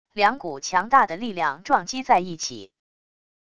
两股强大的力量撞击在一起wav音频